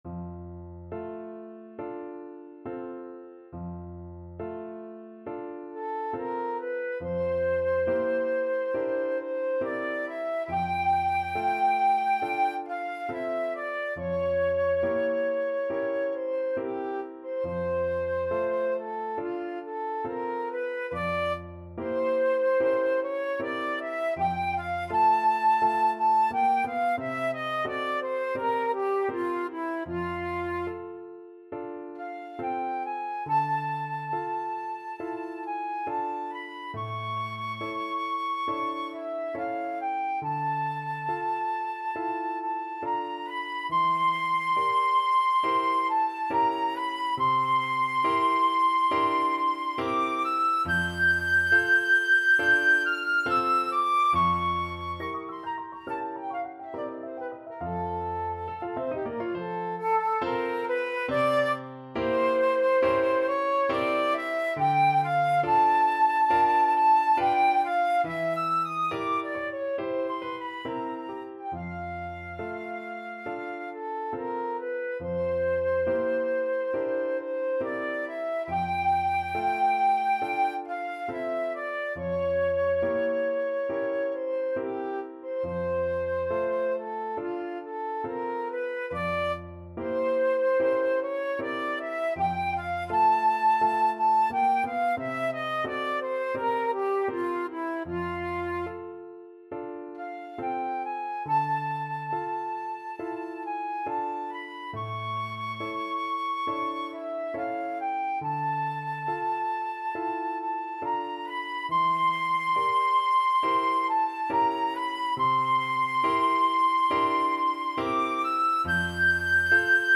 Flute
F major (Sounding Pitch) (View more F major Music for Flute )
4/4 (View more 4/4 Music)
Andante non troppo con grazia =69
Classical (View more Classical Flute Music)